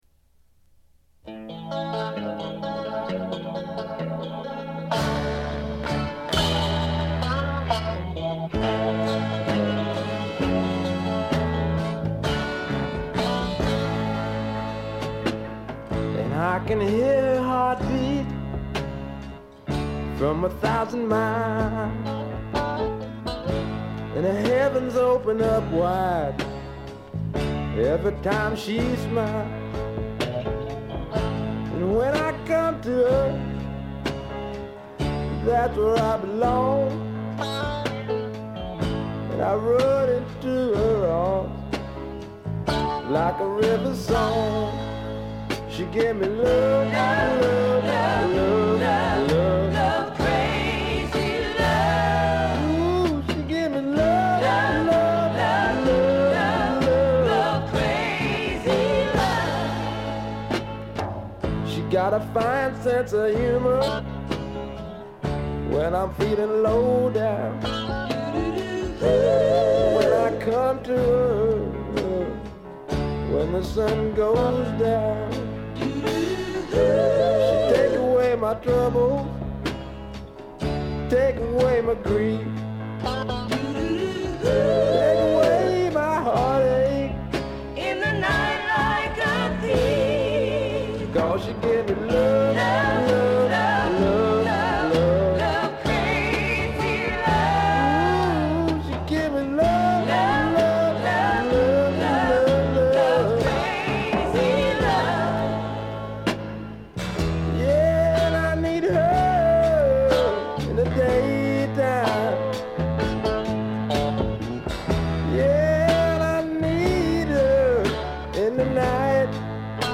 ノイズ感無し。
まさしくスワンプロックの真骨頂。
試聴曲は現品からの取り込み音源です。